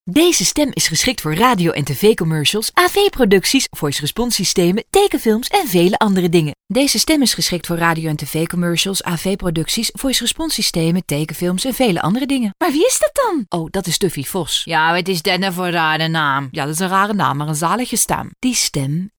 Grabaciones en nuestro estudio de sonido asociado de Holanda.
Locutoras holandesas